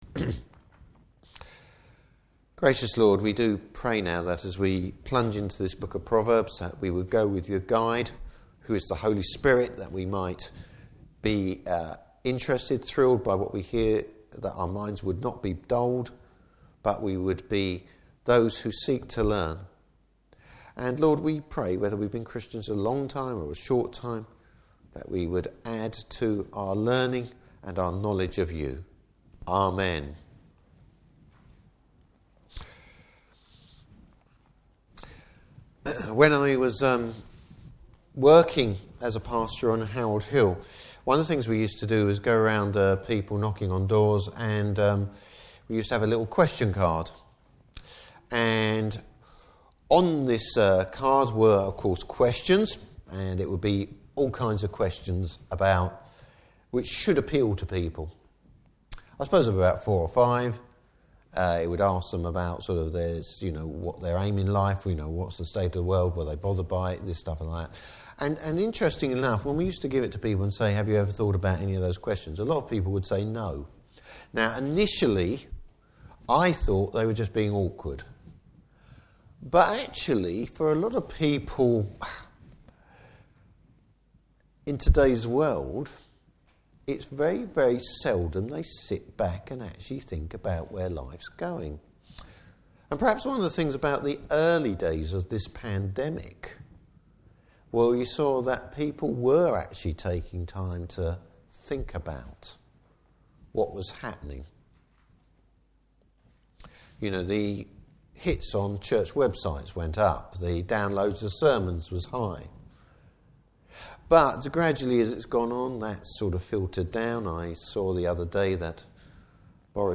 Service Type: Morning Service What is the key to acquiring true wisdom?